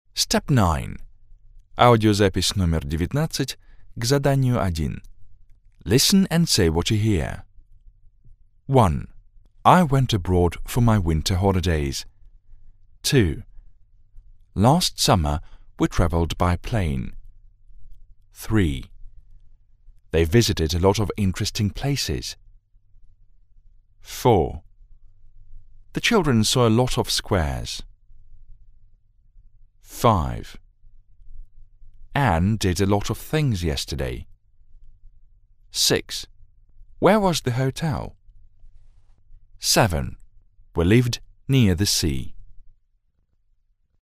3. Аудирование: <